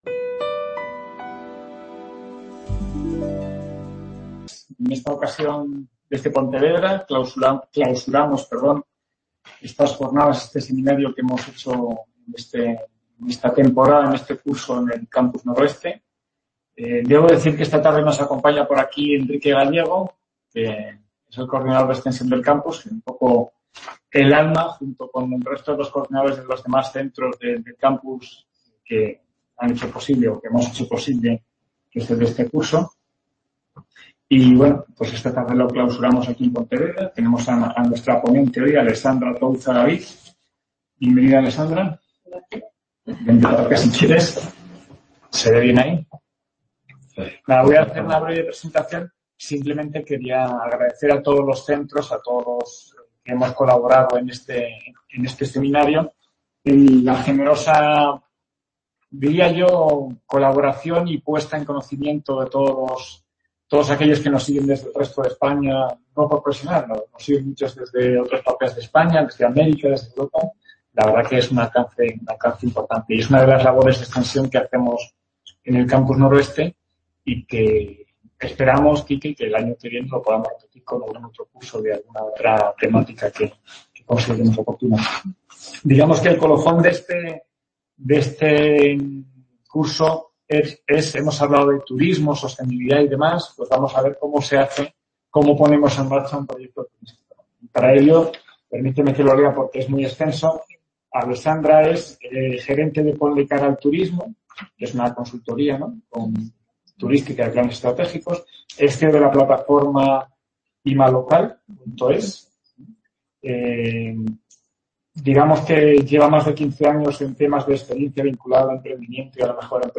Por ello, desde el Campus hemos organizado una serie de conferencias que no solo evidencian esa riqueza patrimonial, con la esperanza de revitalizar la actividad turística, sino que sirven para mostrar algunas de las múltiples herramientas en las que se pueden apoyar los pertenecientes a ese sector tan importante en la economía nacional.